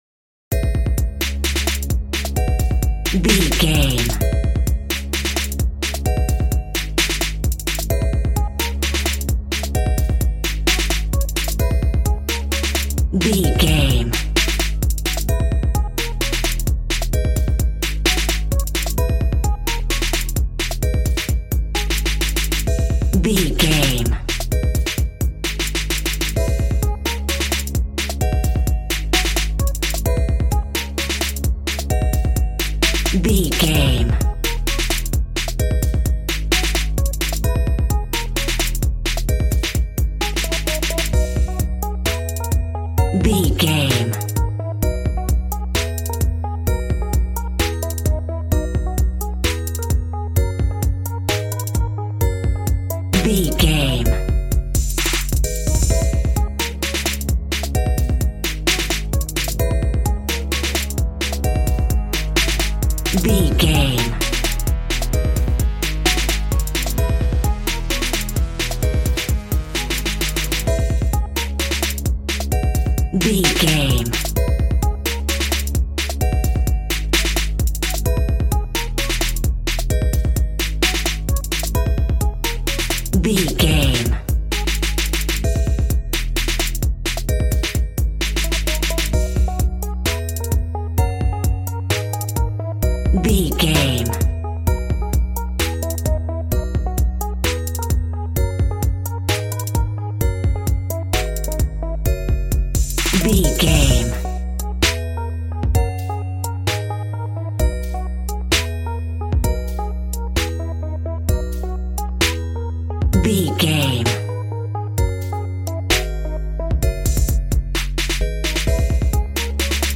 Aeolian/Minor
A♭
calm
smooth
synthesiser
piano